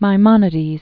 (mī-mŏnĭ-dēz), Moses Originally Moses ben Maimon.